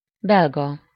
Ääntäminen
Ääntäminen Tuntematon aksentti: IPA: /ˈbɛlɡɒ/ Haettu sana löytyi näillä lähdekielillä: unkari Käännös Substantiivit 1.